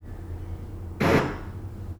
Binaural recordings of broadband noise-bursts played from a loudspeaker in my dining room.
Recordings for 0° azimuth, cropped 1 second before and after the recorded tone’s onset.
Noise bursts (200 ms) with 10 ms on/off ramps (Hanning) were played from a single loudspeaker in my dining room well above average ambient noise levels (~36 dB).